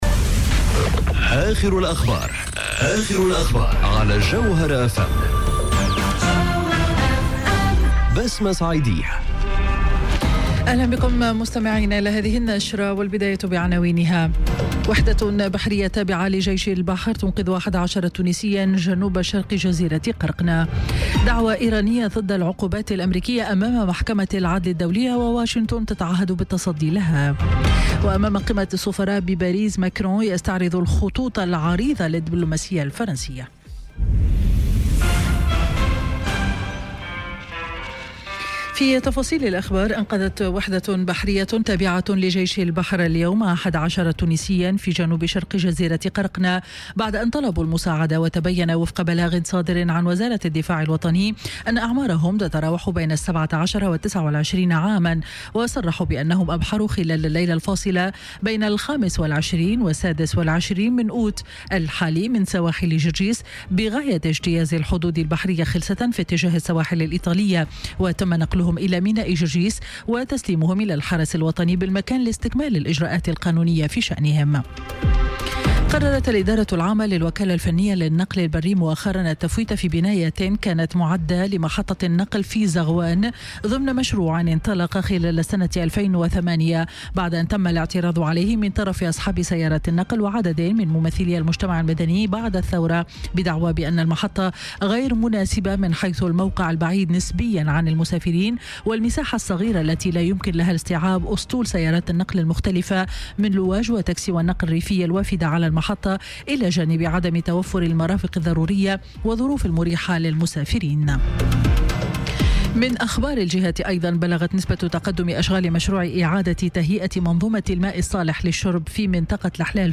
نشرة أخبار السابعة مساء ليوم الاثنين 27 أوت 2018